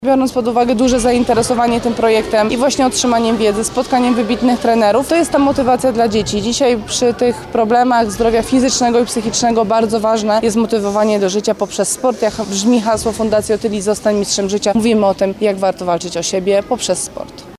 Jestem dumna, że projekt Otylia Swim Tour nabrał już swoją markę– mówi Otylia Jędrzejczak.